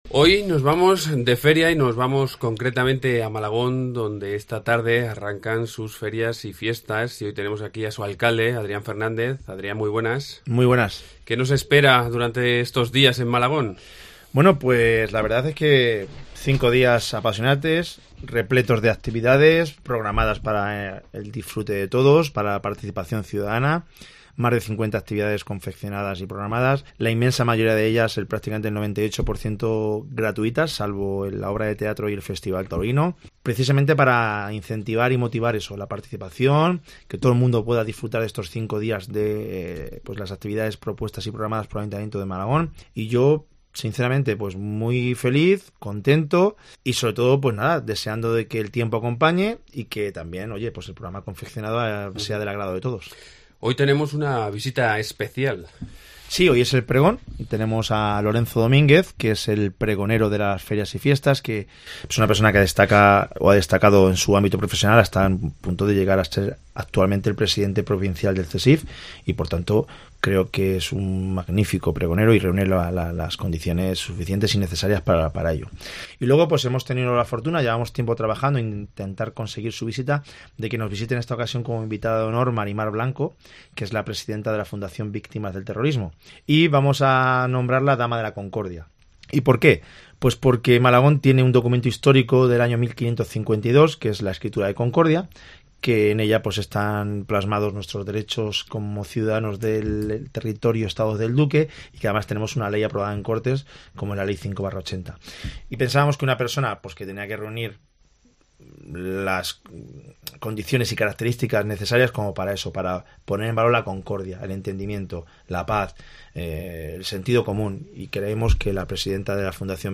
Adrián Fernández, alcalde
Hoy nos visita el alcalde Malagón, Adrián Fernández, y es que esta misma noche arrancan las fiestas de la localidad en honor al Cristo del Espíritu Santo. Un amplio y variado programas de actividades que nos va a desgranar en un momento su alcalde.